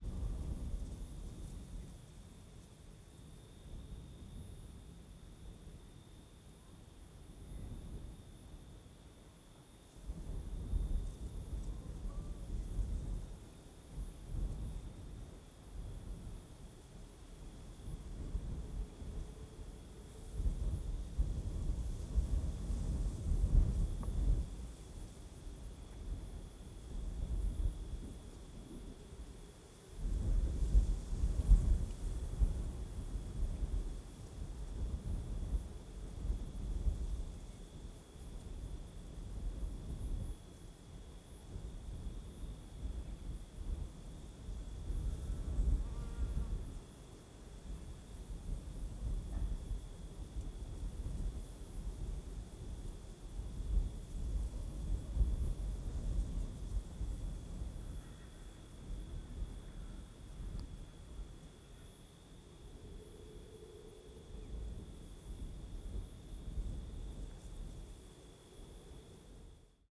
CSC-19-031-GV - Vento em Ambiencia Surround no Mirante de Alto Paraiso Final de Tarde com Grilos.wav